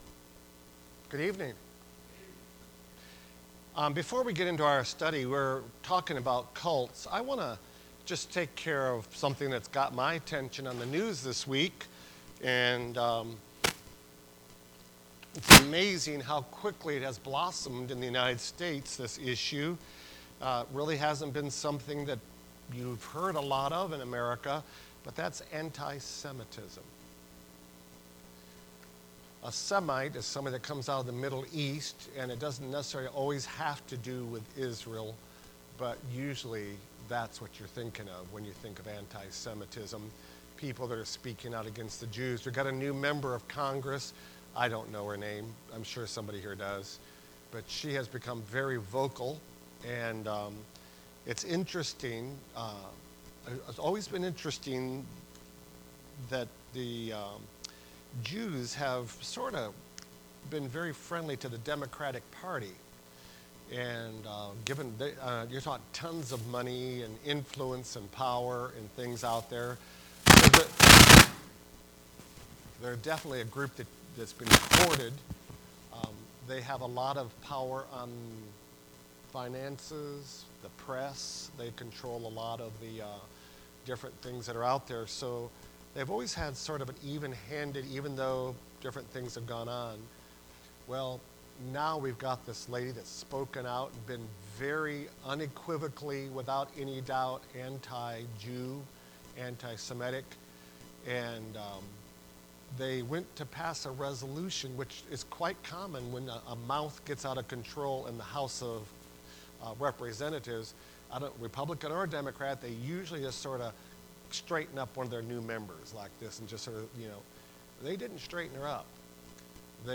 Service Type: Wednesday Prayer Service Preacher